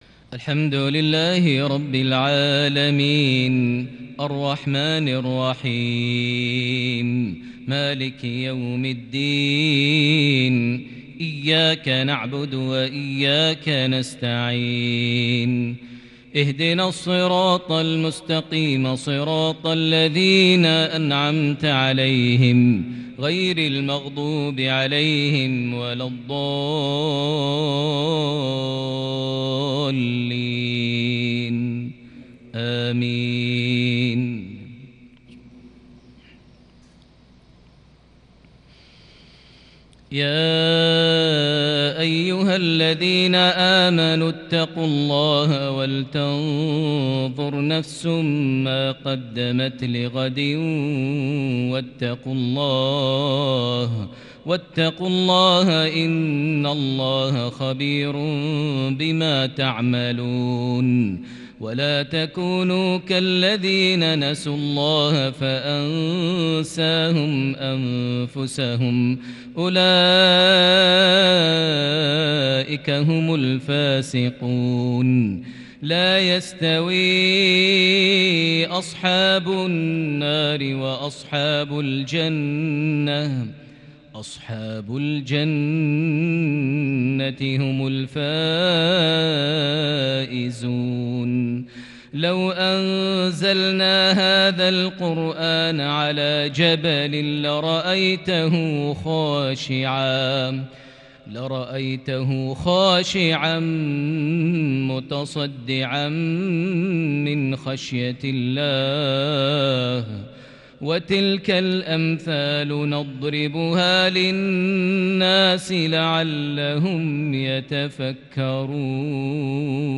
تلاوة شجية بالكرد لخواتيم سورة الحشر | مغرب 6 صفر 1442هـ > 1442 هـ > الفروض - تلاوات ماهر المعيقلي